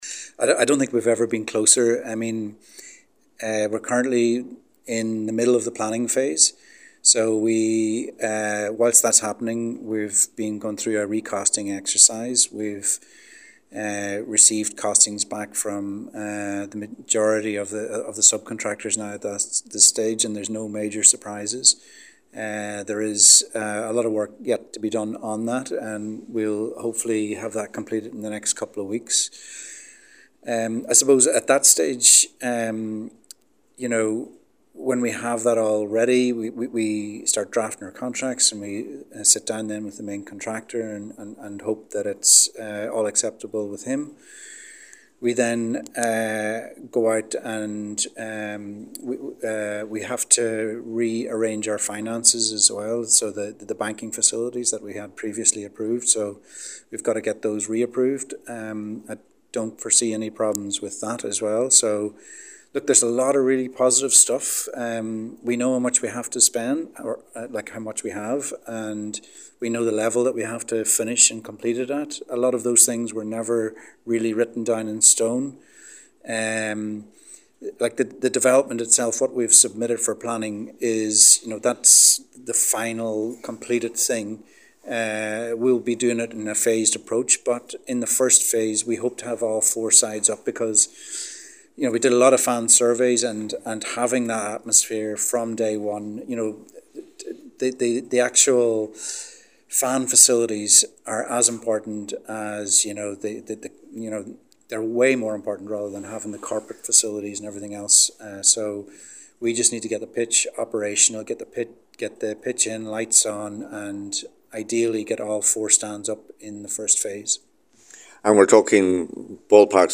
His comments came at a press conference in Ballybofey ahead of the new SSE Airtricity League season which begins this weekend.